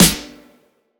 Medicated Snare 40.wav